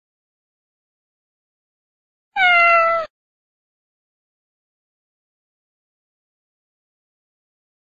دانلود آهنگ میو میو گربه 3 از افکت صوتی انسان و موجودات زنده
جلوه های صوتی
دانلود صدای میو میو گربه3از ساعد نیوز با لینک مستقیم و کیفیت بالا